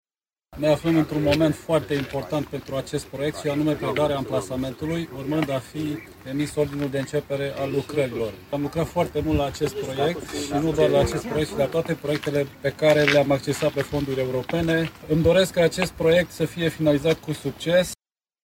Valoarea totală a proiectului depăşeşte 110 milioane de lei, iar banii sunt alocaţi din fonduri europene. Administratorul public al Braşovului, Daniel Gligoraş: